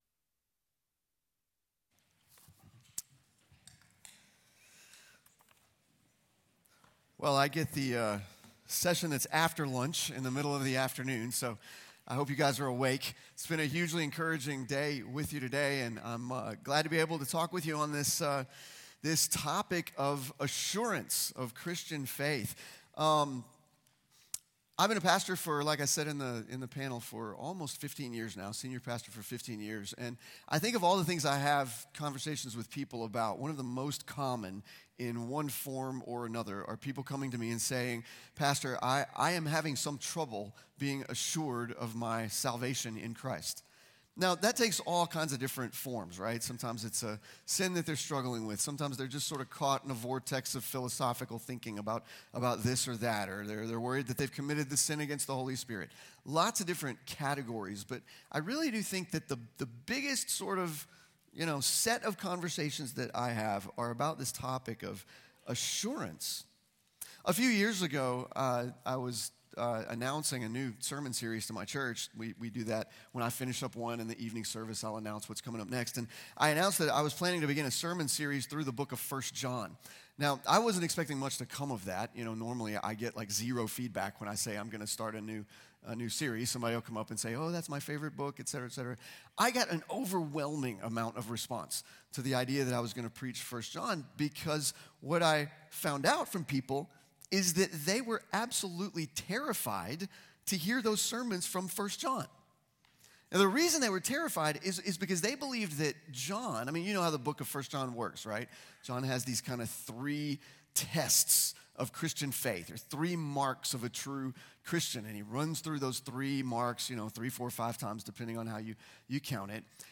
Audio recorded at Feed My Sheep Conference 2025.